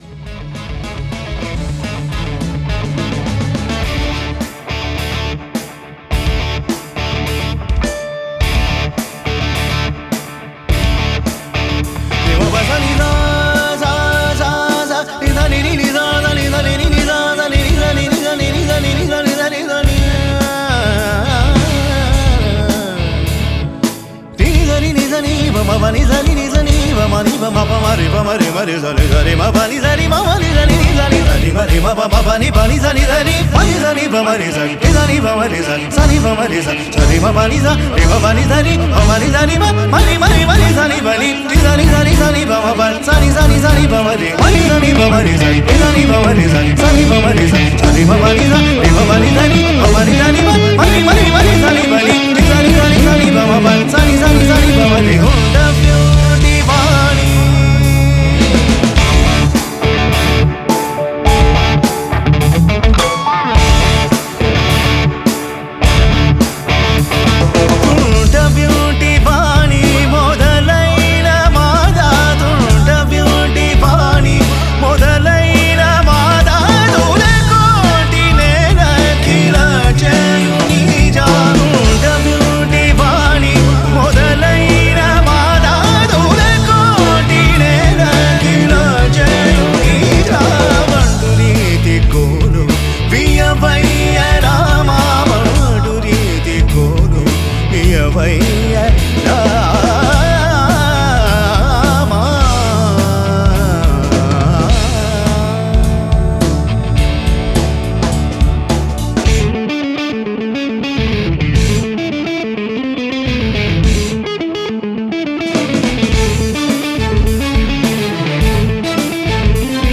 progressive rock